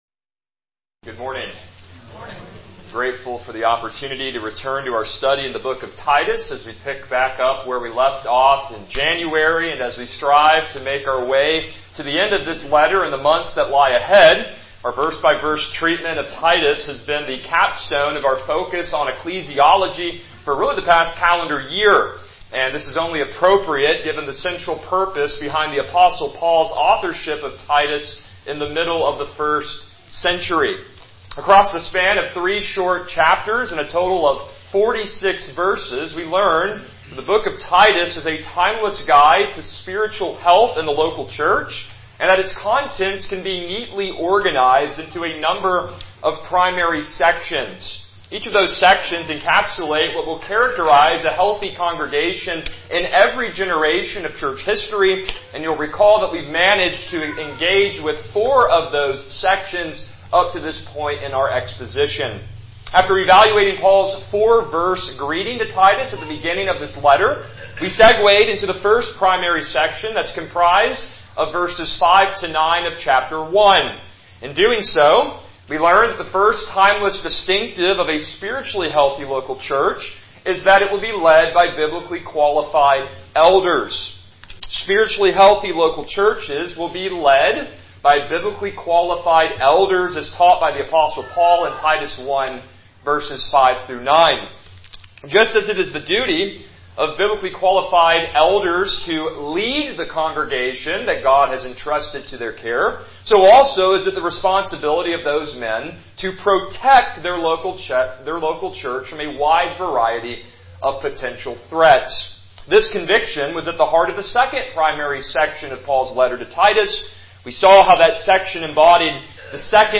Titus 2:15-3:1 Service Type: Morning Worship « Why Should We Sing?